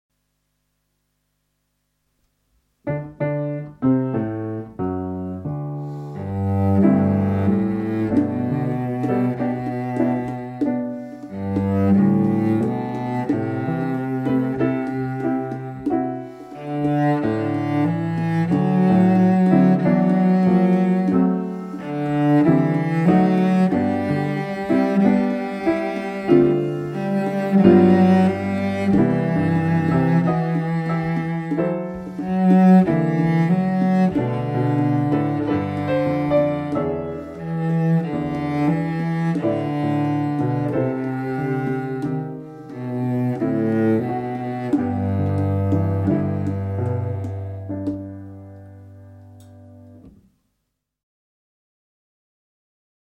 40 Lazy scale (Cello)